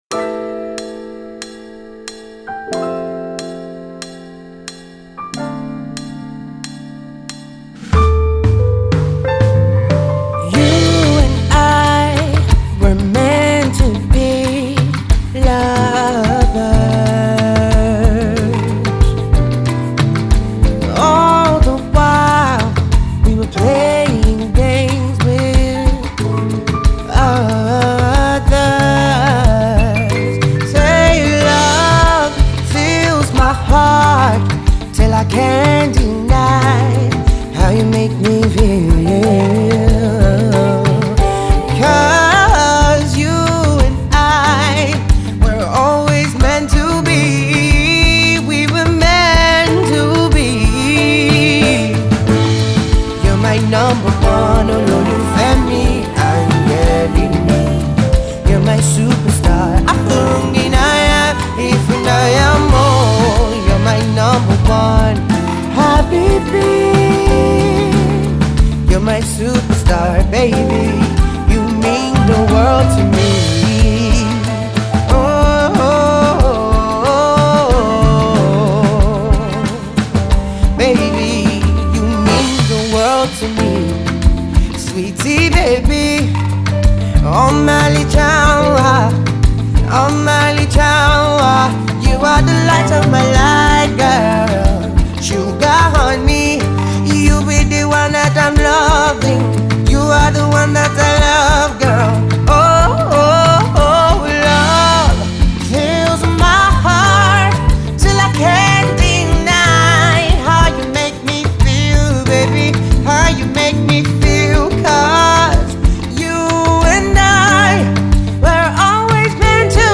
locked in the studio
slow-to-mid-tempo Jazzy Salsa number